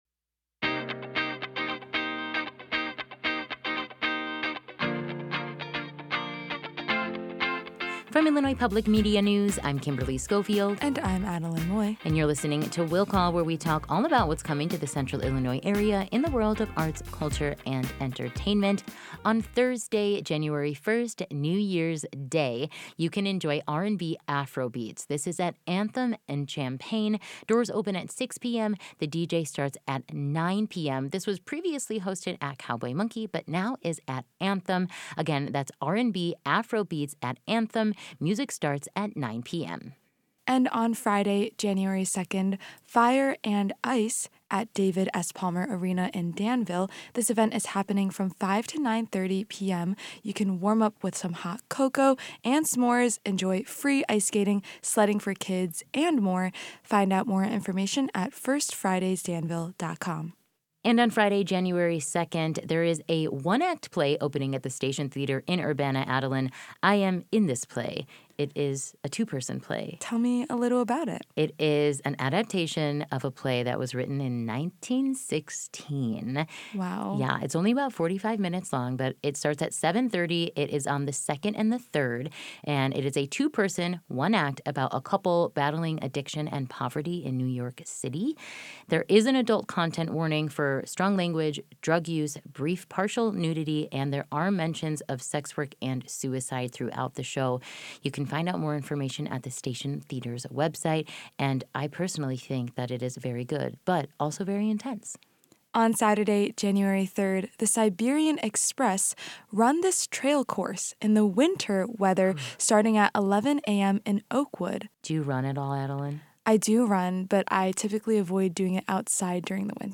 talk about weekend events on IPM News AM 580 and FM 90.9